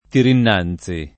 [ tirinn # n Z i ]